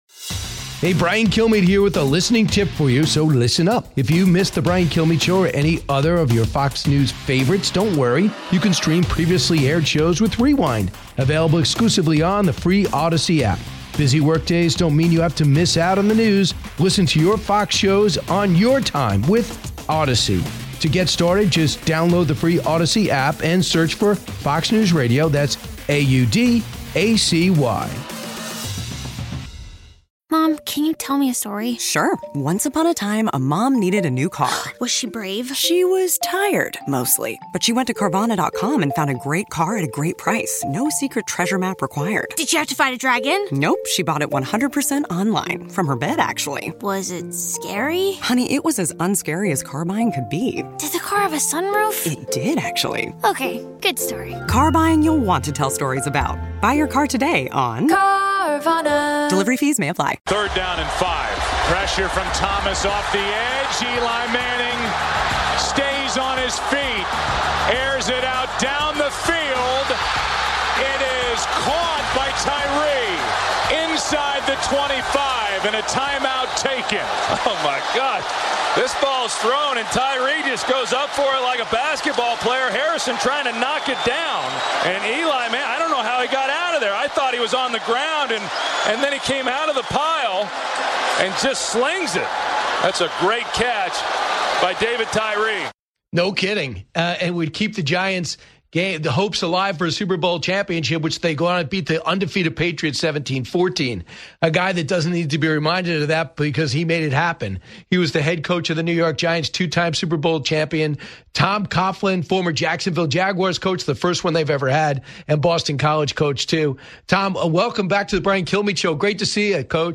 Legendary New York Giants Head Coach Tom Coughlin joins Brian Kilmeade to take us inside the huddle of the greatest Super Bowl run in history.